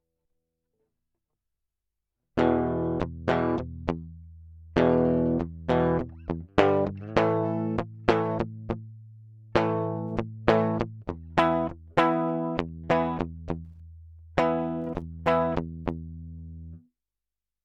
Schecter c-1 elite sh6 bridge DDean Usa Baby Z BKP holydiver bridge E�� ����� ��� ����� ���� � ���������� ������, �������� �� �...